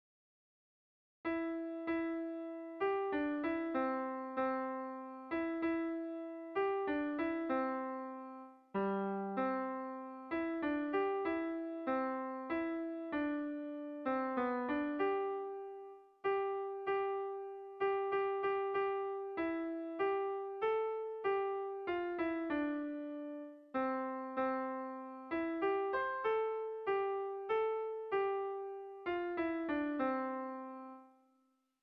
Zortziko txikia (hg) / Lau puntuko txikia (ip)
ABDE